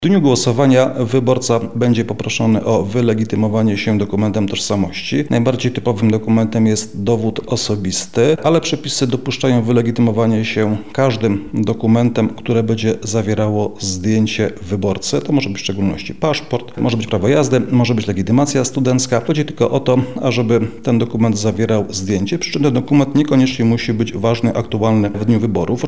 –  mówi sędzia Krzysztof Niezgoda, przewodniczący Okręgowej Komisji Wyborczej w Lublinie